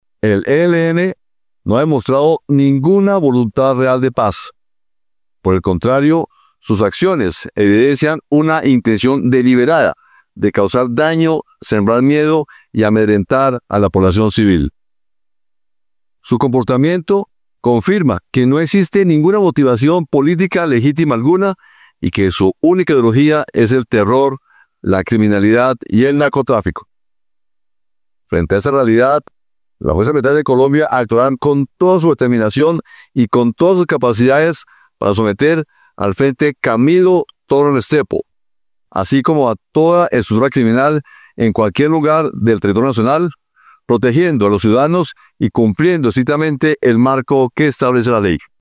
Por su parte el Comandante de las Fuerzas Militares Almirante Francisco Cubides, se refirió a la ausencia de una voluntad real de paz de parte del grupo al margen de la Ley.